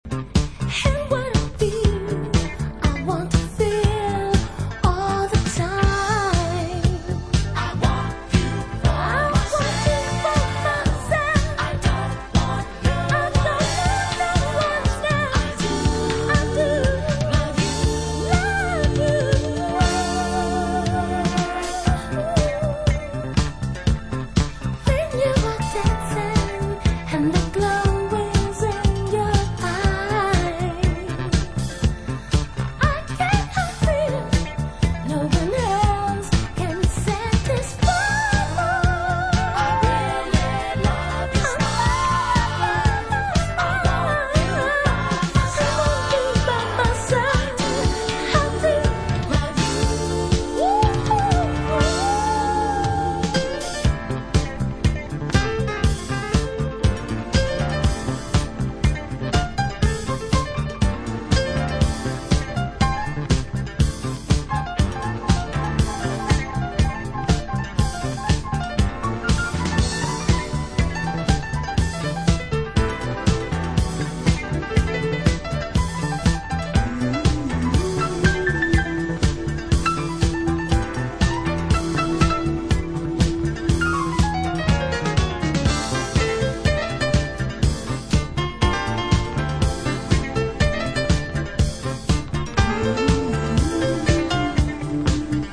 Eternal disco, funk tracks that will never go out of style.